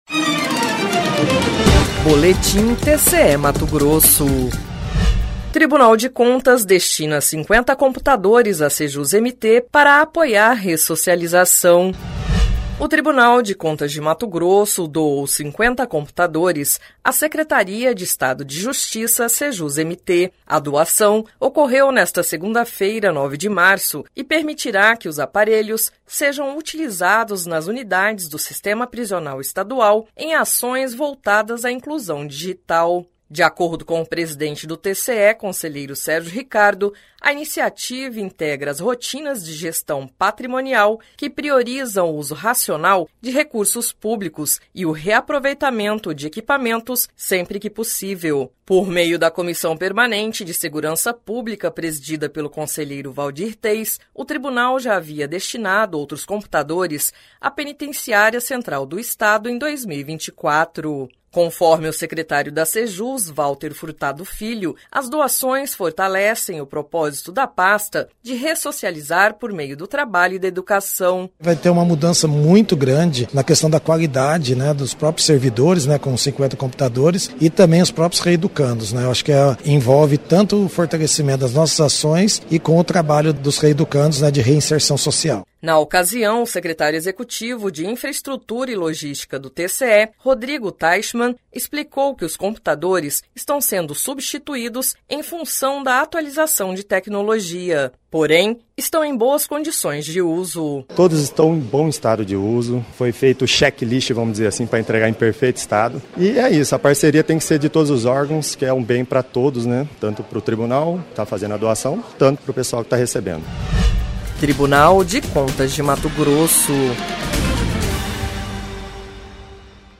Sonora: Valter Furtado Filho - secretário da Sejus-MT